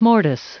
Prononciation du mot mortice en anglais (fichier audio)
Prononciation du mot : mortice